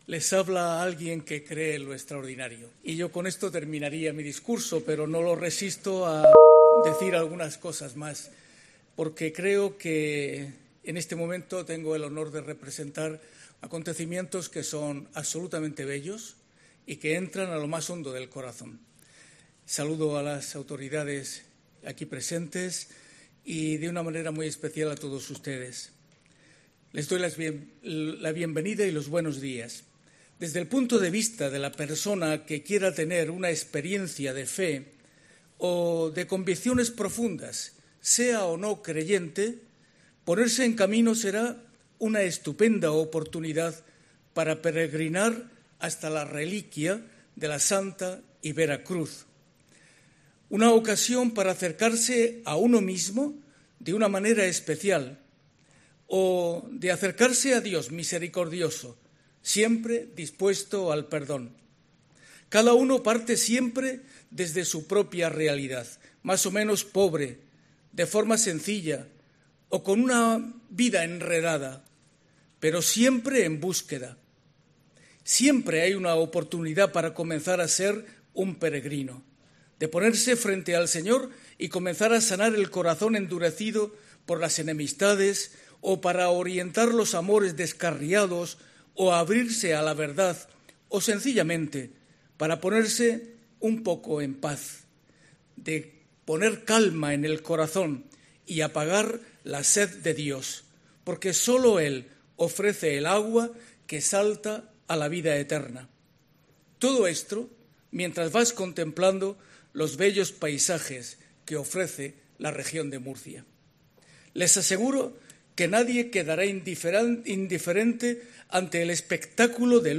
Monseñor Lorca Planes, Obispo de la Diócesis de Cartagena